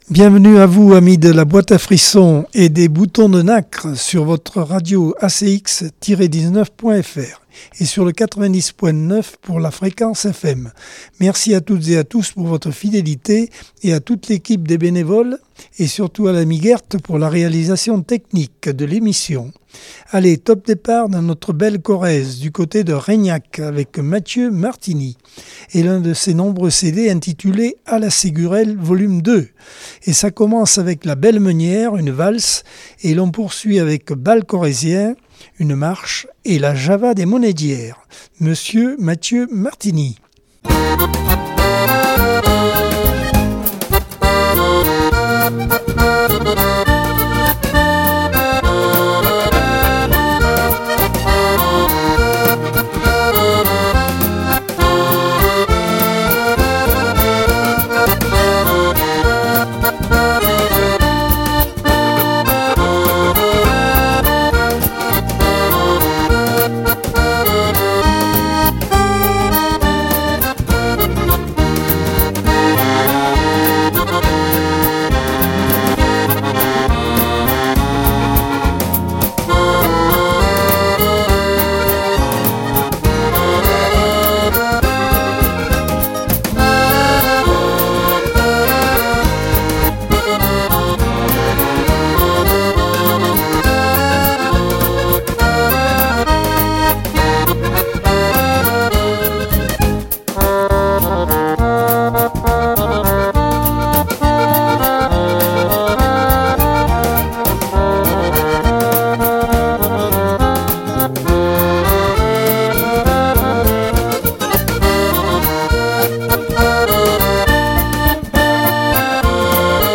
Accordeon 2024 sem 45 bloc 1 - Radio ACX